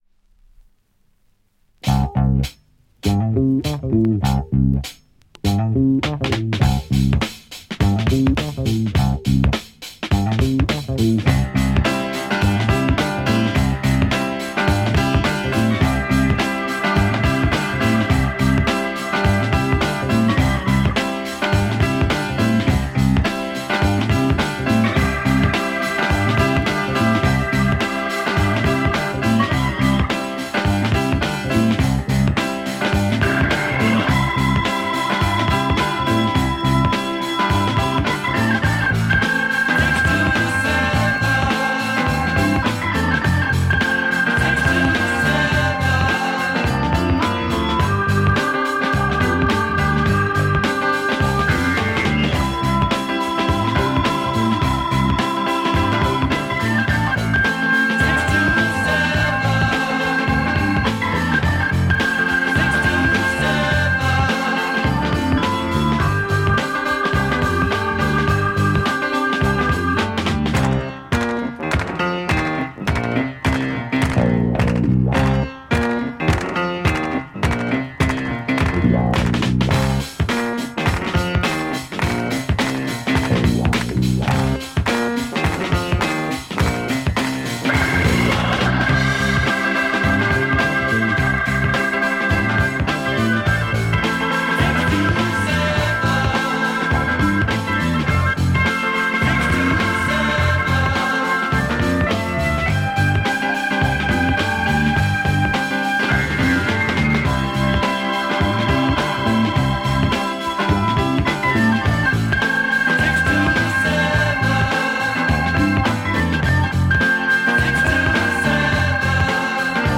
Austrian Progressive underground funk